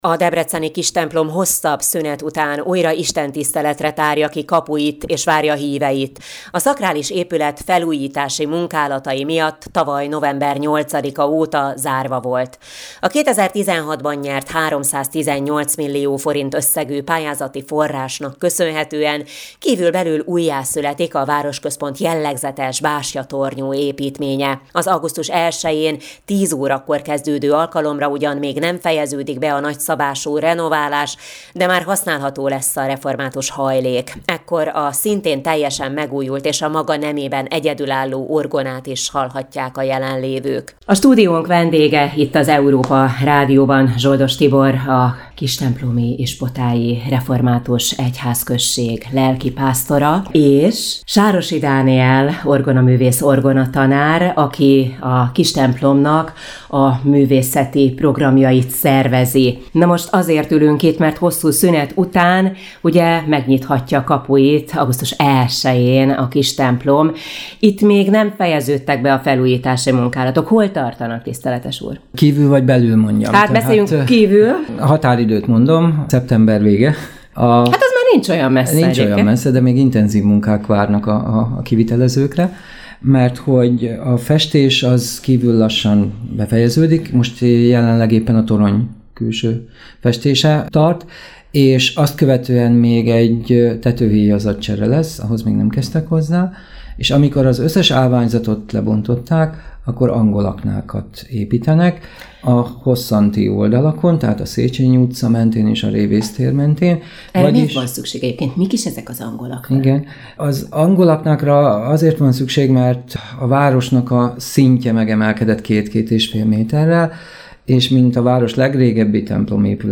Európa Rádióban